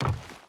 Wood Run 4.wav